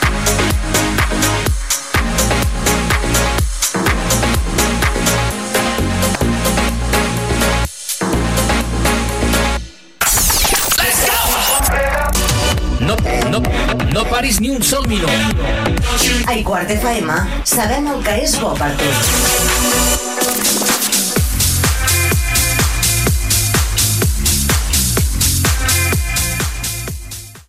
311cef687ecfa0723d05f44f2d55af1ce69e39b1.mp3 Títol IQuart FM Emissora IQuart FM Titularitat Pública municipal Descripció Tema musical, indicatiu de l'emissora, hora, tema musical.